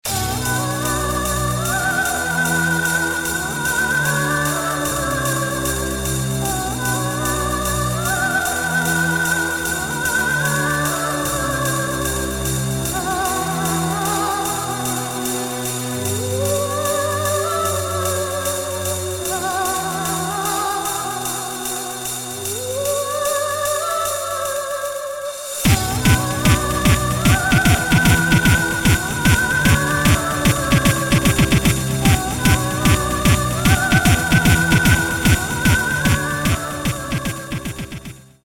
Рингтоны Электроника